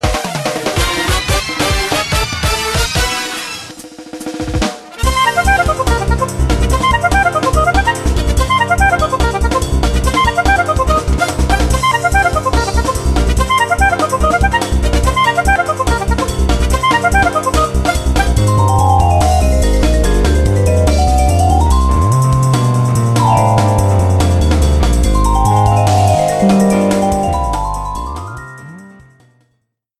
Alternate background music
Reduced length to 30 seconds, with fadeout.